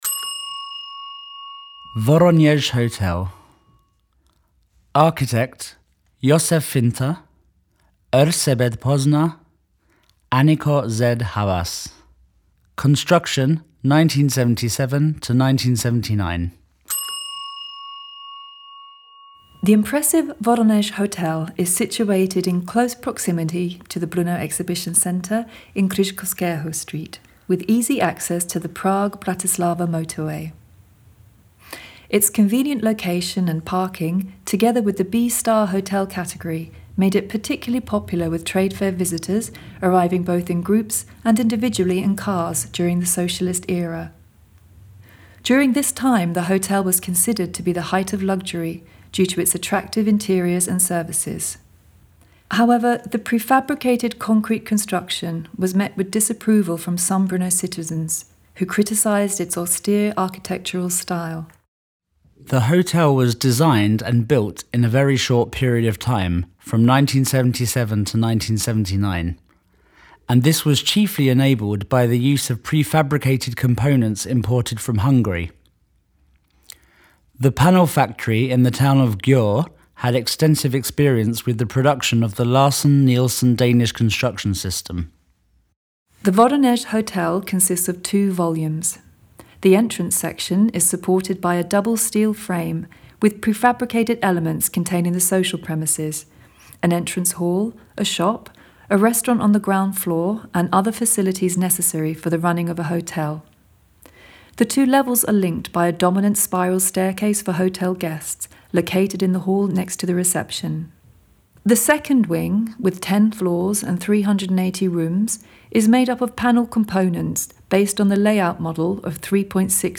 audioprůvodce